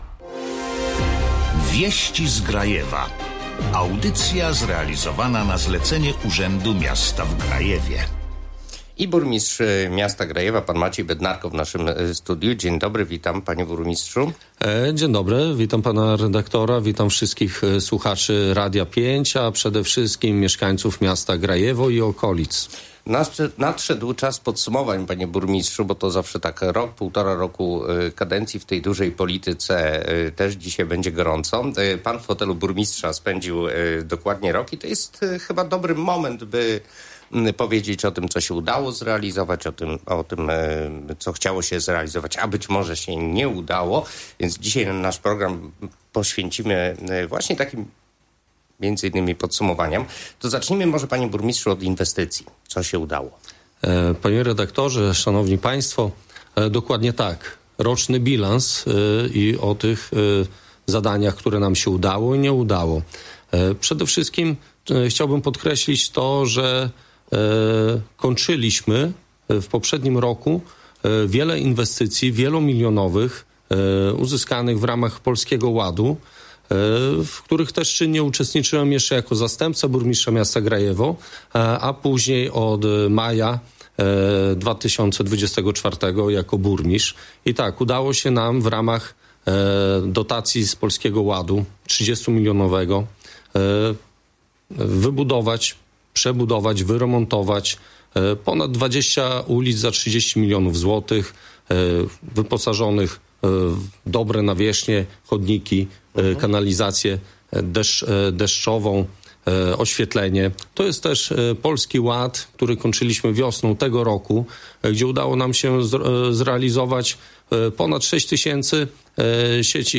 Grajewo Rok kadencji na fotelu burmistrza podsumował dziś (11.06) w Radiu 5 podczas audycji "Wieści z Grajewa" włodarz tego miasta
11.06-Maciej-Bednarko-burmistrz-Grajewa-Wiesci-z-Grajewa.mp3